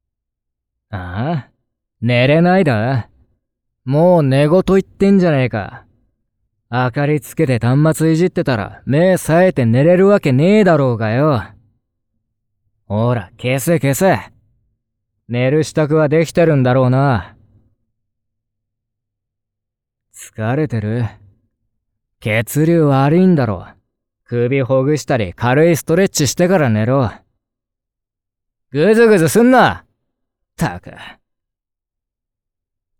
寝かしつけボイス〜辛口はよ寝ろver〜 | PandoraPartyProject